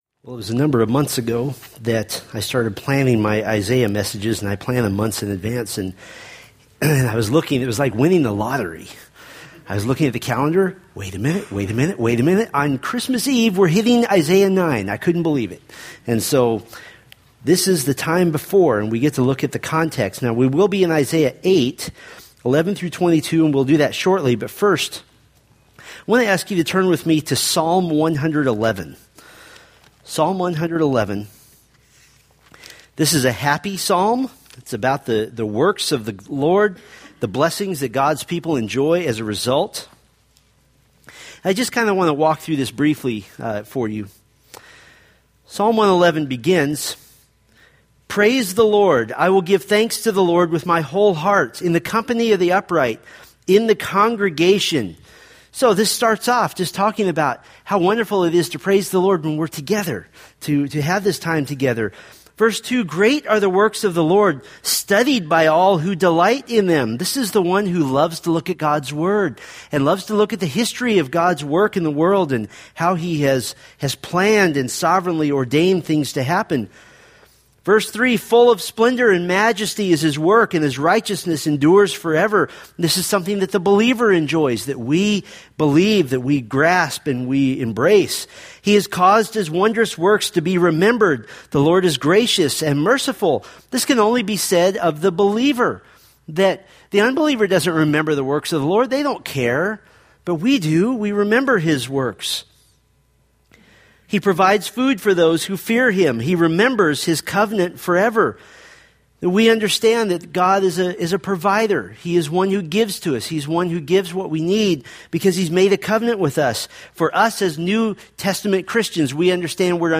Preached December 20, 2015 from Isaiah 8:10-22